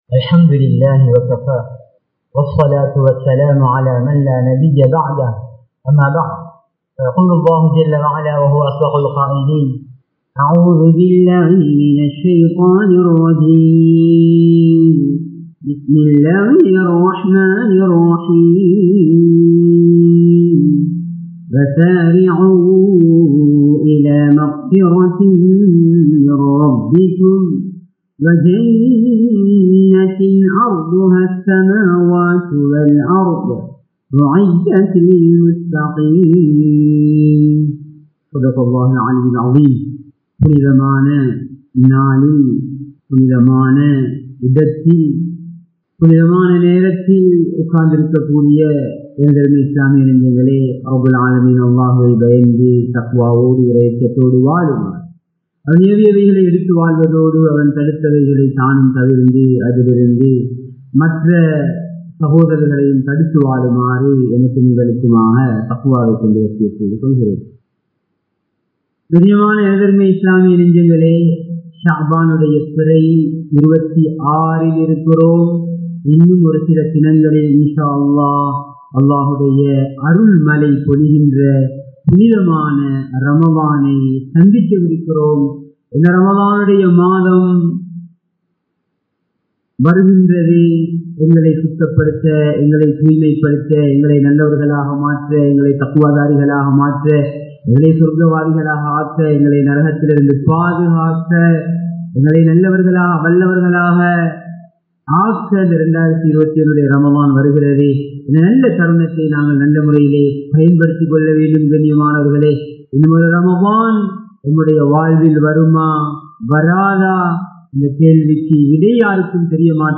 ரமழானை இபாதத்களால் அலங்கரிப்போம் | Audio Bayans | All Ceylon Muslim Youth Community | Addalaichenai
Muhiyadeen Jumua Masjith